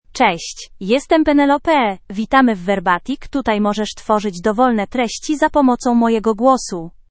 PenelopeFemale Polish AI voice
Penelope is a female AI voice for Polish (Poland).
Voice sample
Female
Penelope delivers clear pronunciation with authentic Poland Polish intonation, making your content sound professionally produced.